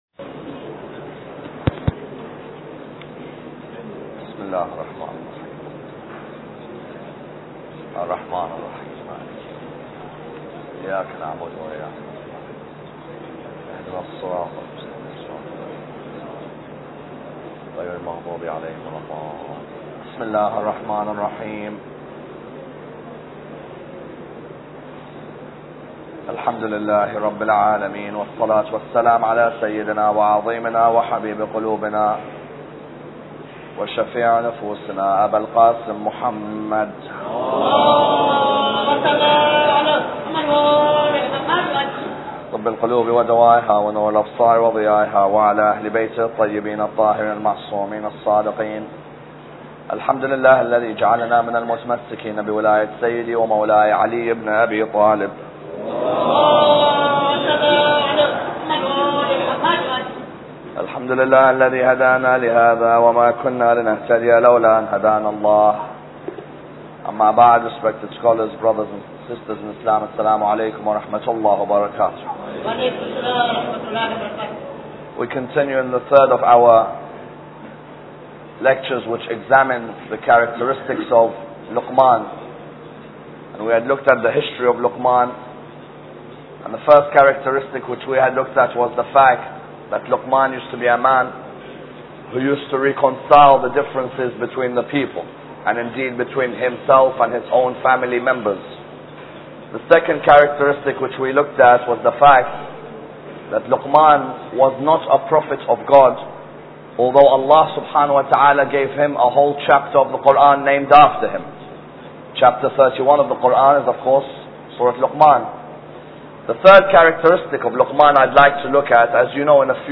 Lecture 3